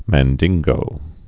(măn-dĭnggō)